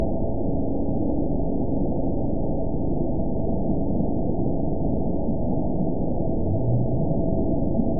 event 922870 date 04/29/25 time 15:28:14 GMT (1 month, 2 weeks ago) score 8.81 location TSS-AB02 detected by nrw target species NRW annotations +NRW Spectrogram: Frequency (kHz) vs. Time (s) audio not available .wav